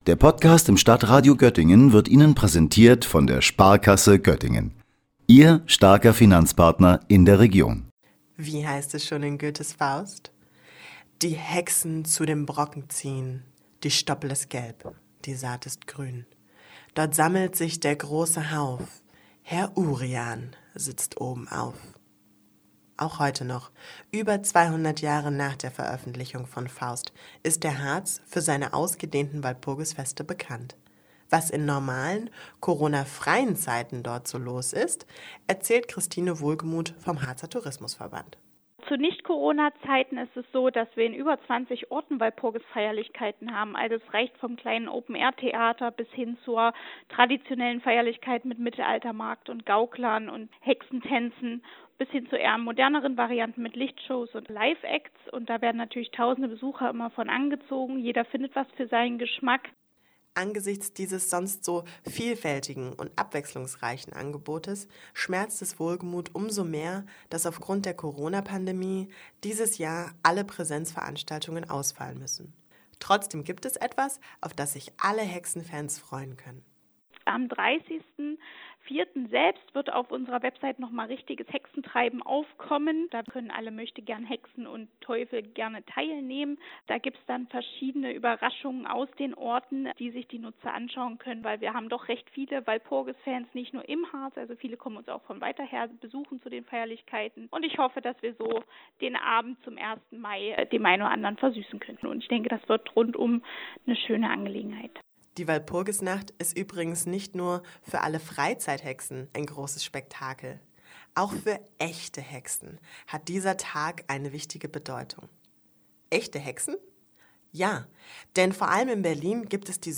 Knarzende Äste, Eulenrufe, Zaubersprüche; Gruselstimmung.